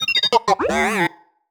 sci-fi_driod_robot_emote_01.wav